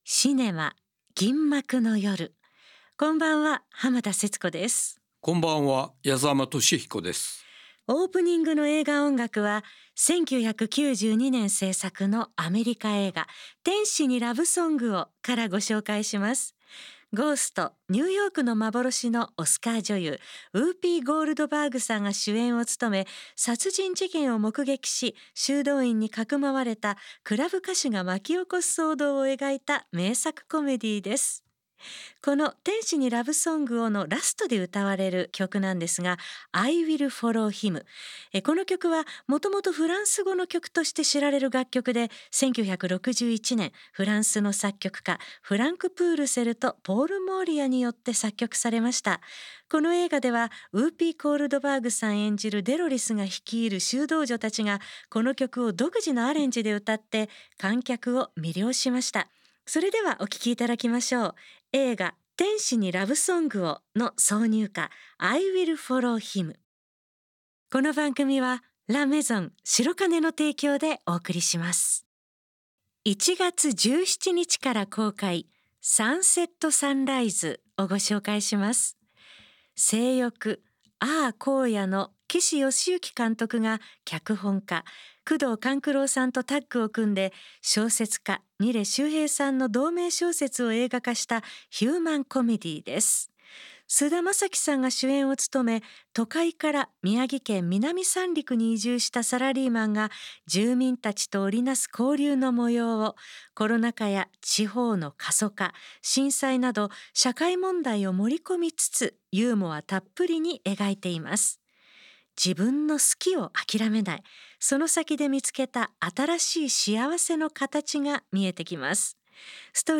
最新の映画情報と過去の名作映画を音楽と共に紹介する30分。